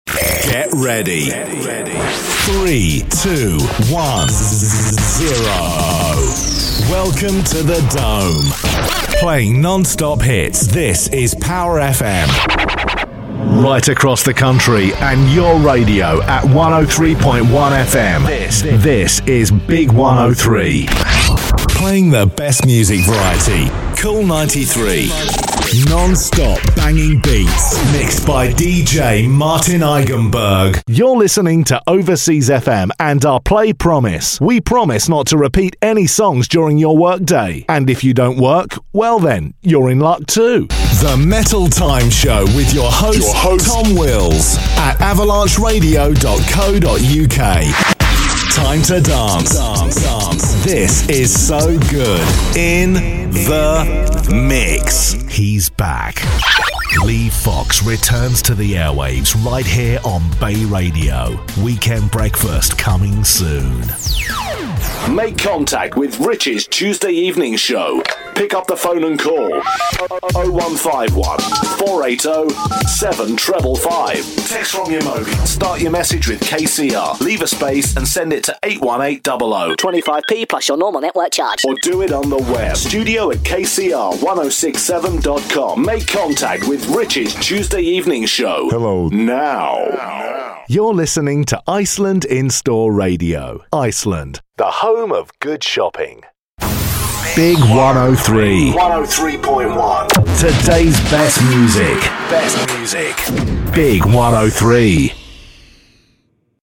English (British)
Radio Imaging
Mic: Neumann U87